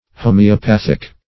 Homeopathic \Ho`me*o*path"ic\, a. [Cf. F. hom['e]opathique.]